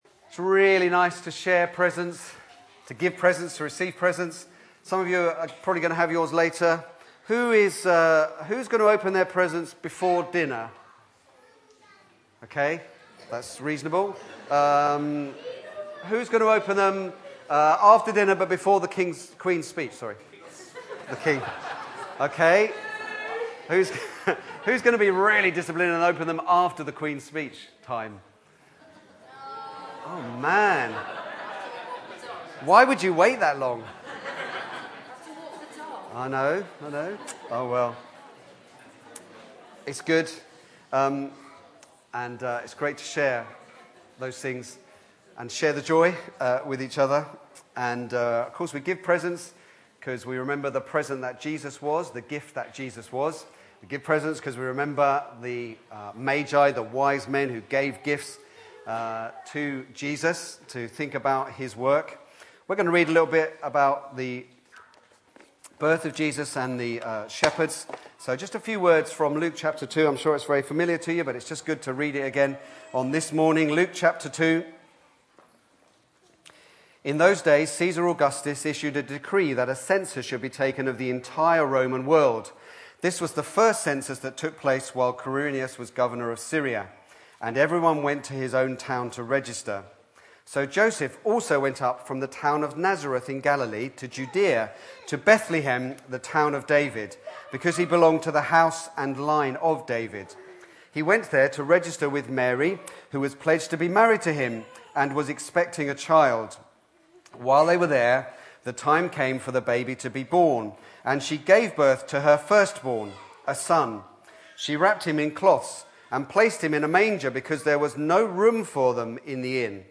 Back to Sermons This Christmas Day remember…